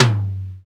TOM XC.TOM05.wav